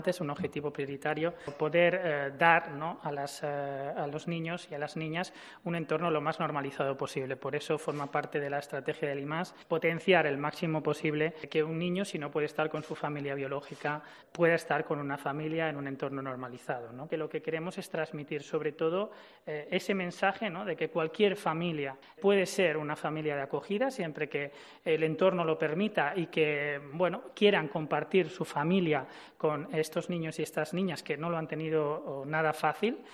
Javier de Juan, presidente del IMAS